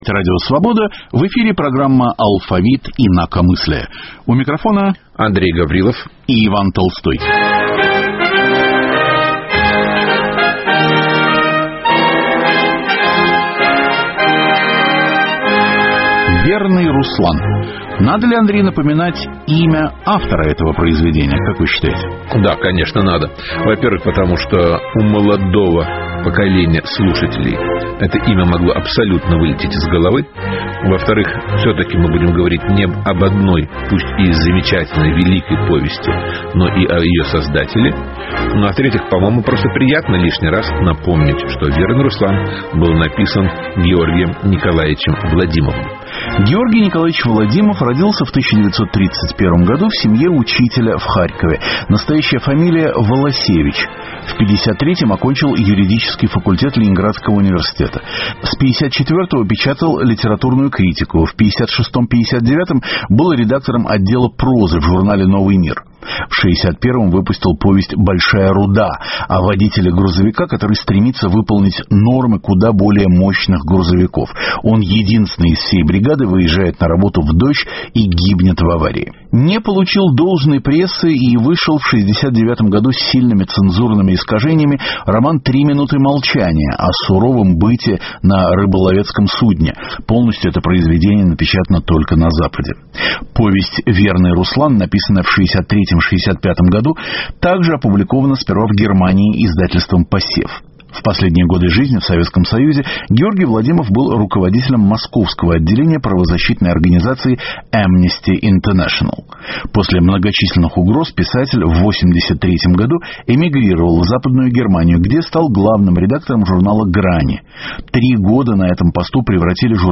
Программа посвящена знаменитой повести Георгия Владимова о караульной собаке, оставшейся без работы в расформированном сталинском лагере. Звучат архивные отзывы на книги Владимова - выступления Андрея Синявского, Сергея Довлатова, документы эпохи и голос писателя.